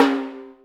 ORCH TIMY1-S.WAV